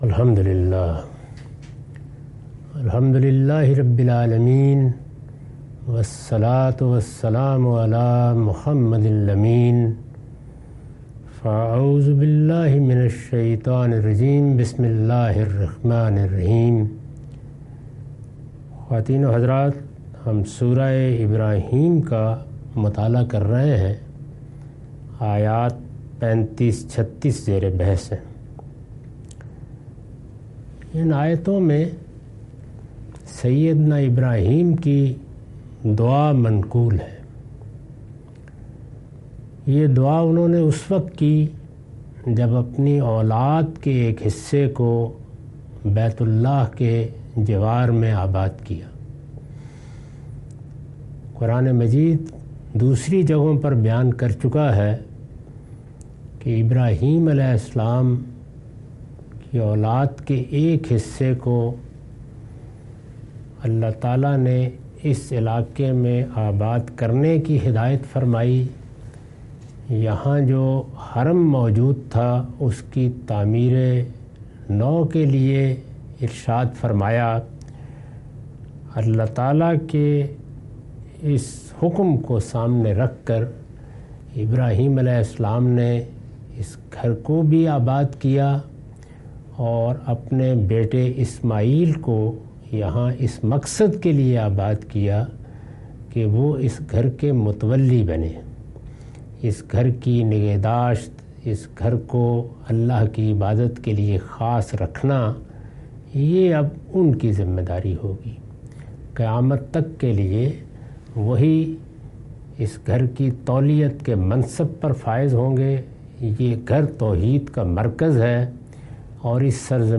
Surah Ibrahim- A lecture of Tafseer-ul-Quran – Al-Bayan by Javed Ahmad Ghamidi. Commentary and explanation of verses 35-38.